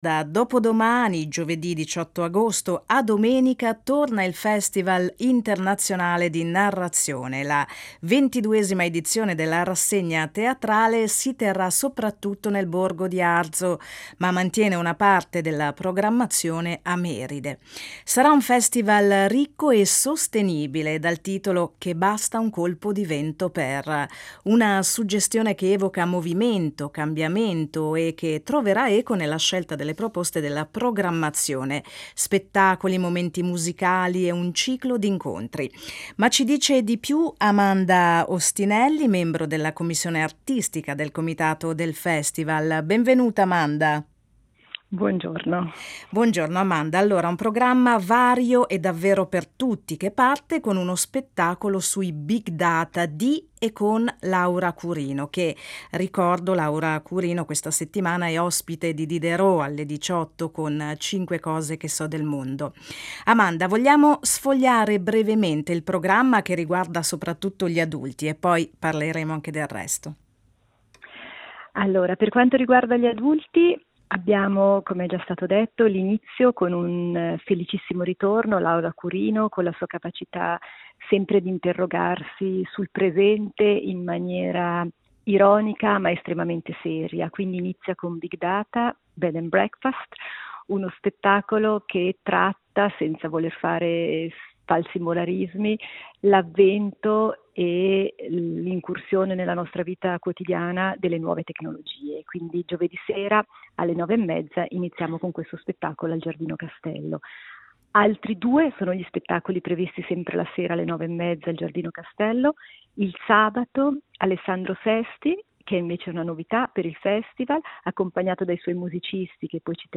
Ne parliamo in Finestra aperta con
membro della commissione artistica del comitato del Festival di narrazione di Arzo.